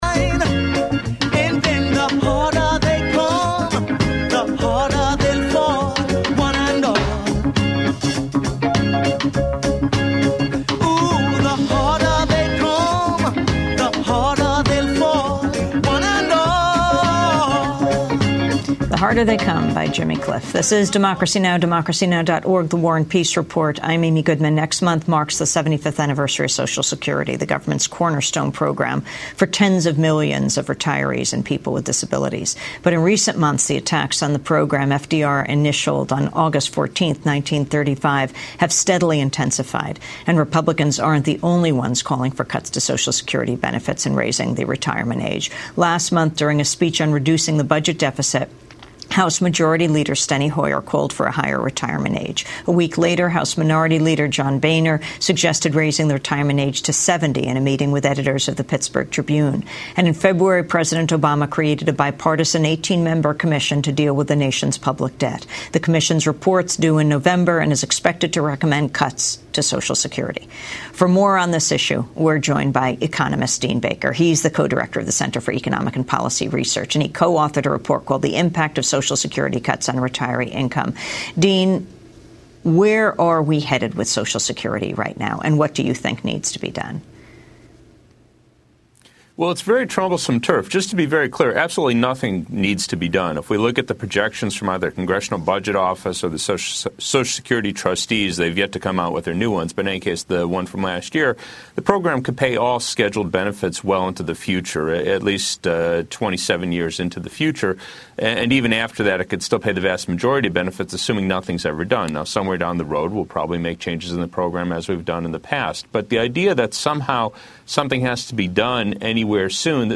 Local activists from...